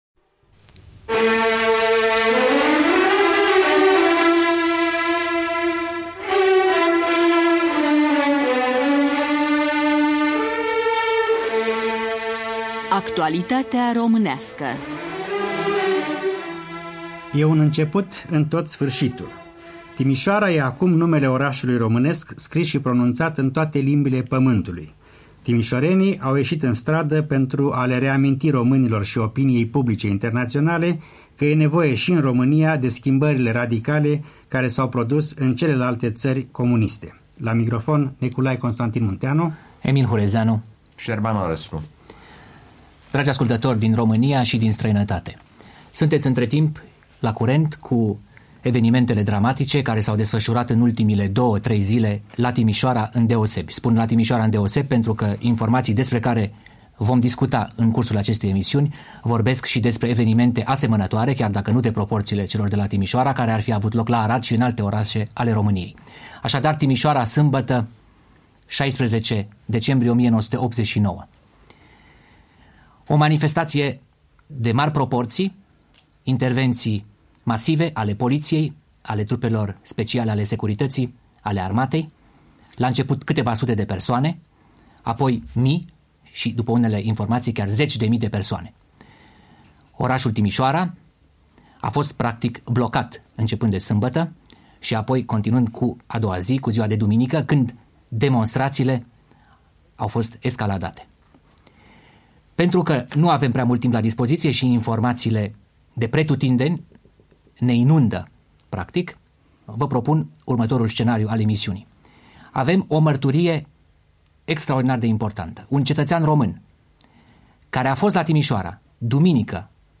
Timișoara, începînd de la 16 decembrie 1989, evenimente evocate de martori oculari și mesajul regelui Mihai I.